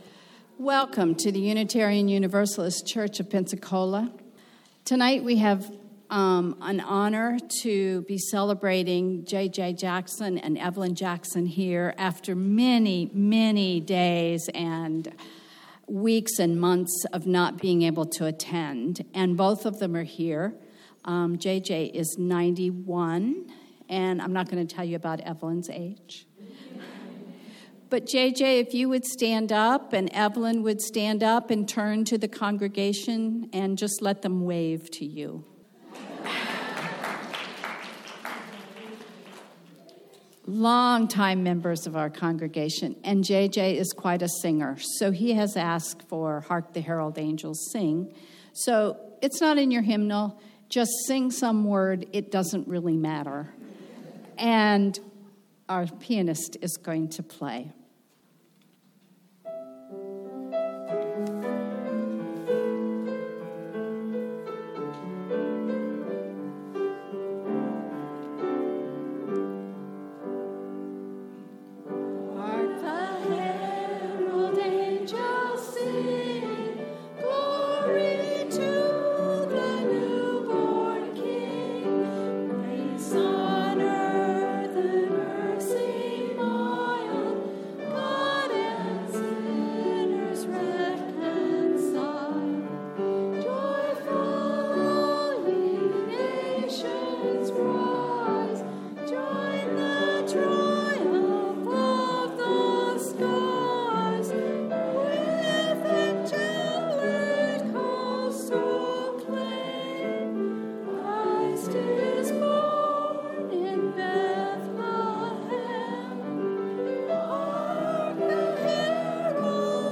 Christmas Eve Candlelight Service - Unitarian Universalist Church of Pensacola
Bring family, friends and guests for a special Service for All Ages of carols and readings concluding with our traditional candle lighting.